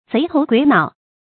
賊頭鬼腦 注音： ㄗㄟˊ ㄊㄡˊ ㄍㄨㄟˇ ㄣㄠˇ 讀音讀法： 意思解釋： 同「賊頭鼠腦」。